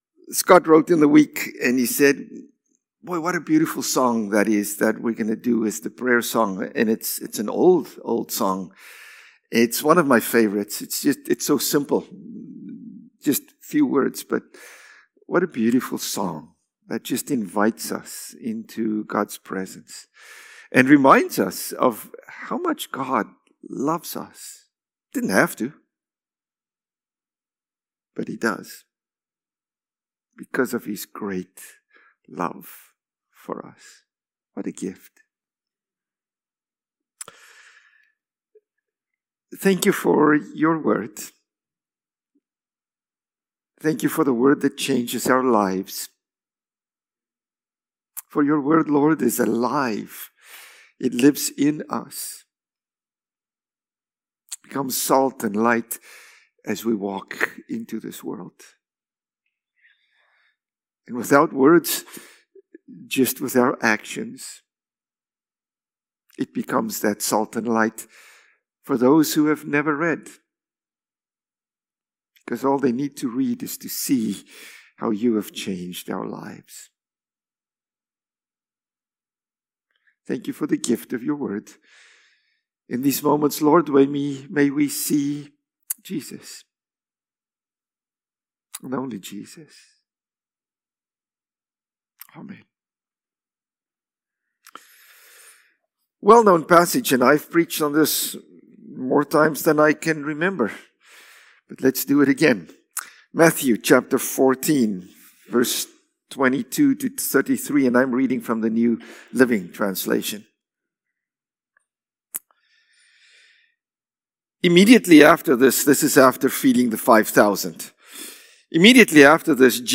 January-12-Sermon.mp3